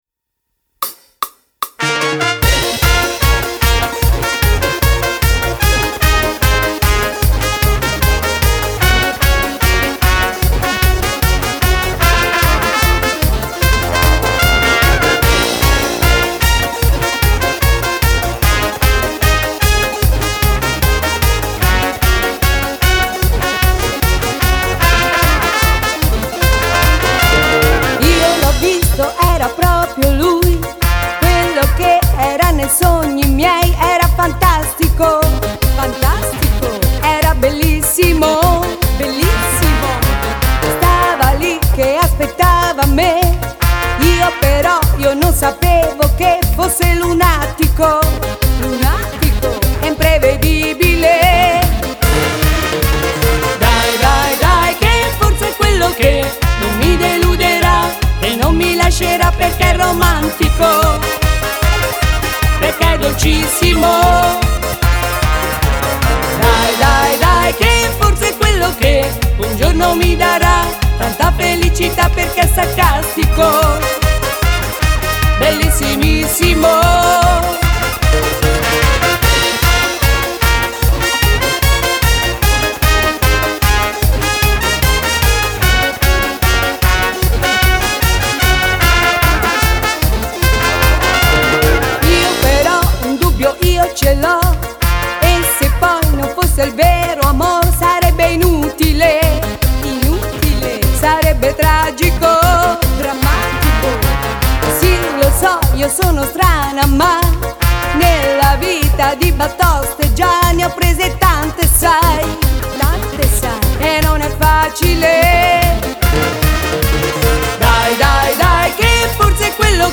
VERSIONE FEMMINILE
Merengue